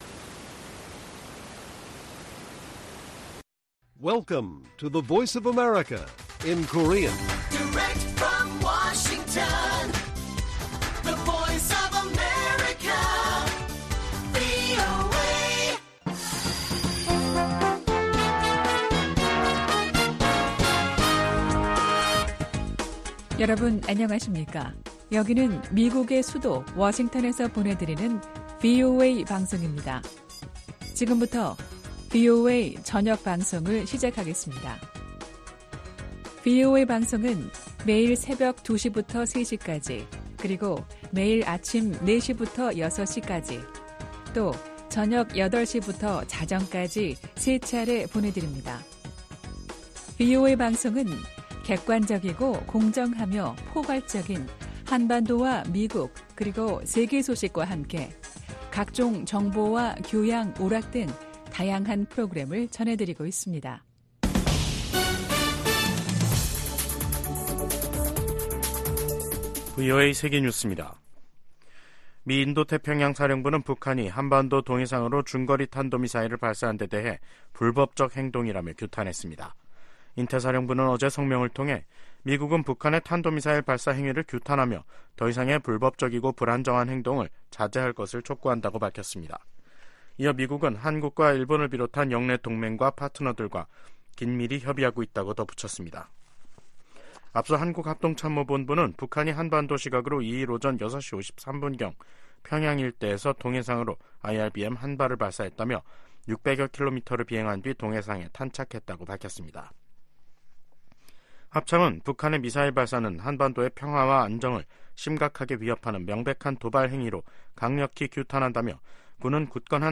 VOA 한국어 간판 뉴스 프로그램 '뉴스 투데이', 2024년 4월 2일 1부 방송입니다. 북한이 보름 만에 또 다시 중거리 극초음속 미사일로 추정되는 탄도미사일을 동해상으로 발사했습니다. 미국은 러시아가 북한 무기를 받은 대가로 유엔 대북제재 전문가패널의 임기 연장을 거부했다고 비판했습니다. 주한미군이 중국과 타이완 간 전쟁에 참전할 경우 한국도 관여를 피하기 어려울 것으로 전 주일미군사령관이 전망했습니다.